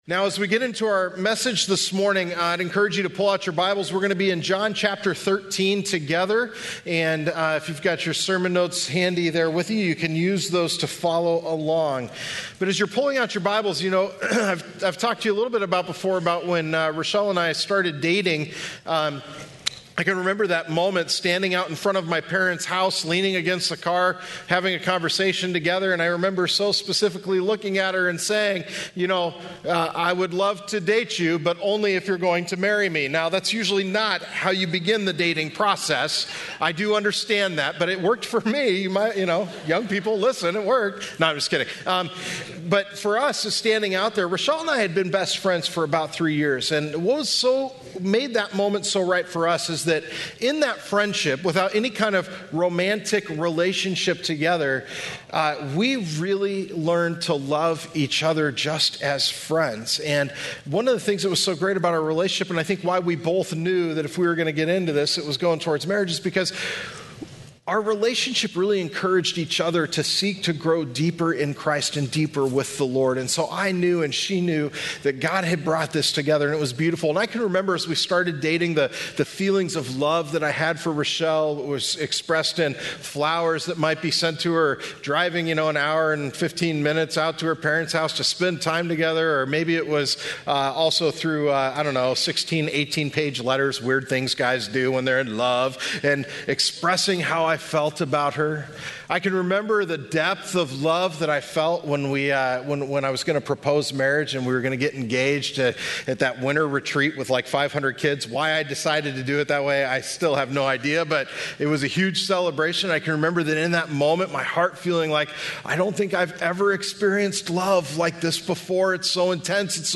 Westgate Chapel Sermons Passion - Reflect My Love Apr 14 2019 | 00:43:48 1x 00:00 / 00:43:48 Subscribe Share Apple Podcasts Overcast RSS Feed Share Link Embed